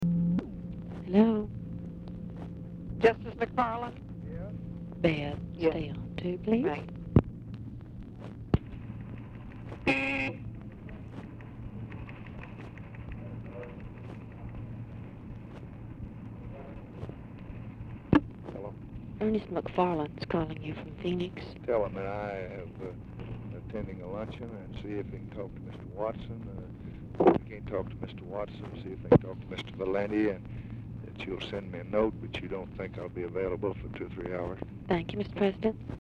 Telephone conversation # 9805, sound recording, LBJ and ERNEST MCFARLAND, 3/1/1966, 1:55PM?
Format Dictation belt
Location Of Speaker 1 Oval Office or unknown location
Other Speaker(s) OFFICE SECRETARY, TELEPHONE OPERATOR